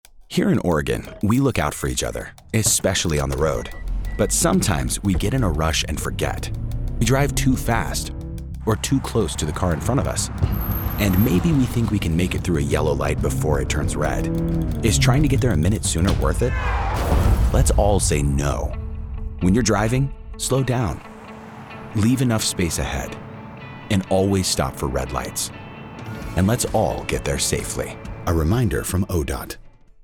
"Is it Worth it" Radio PSA - 30 seconds
SPEED_30s_English_Radio_Is_It_Worth_It_.mp3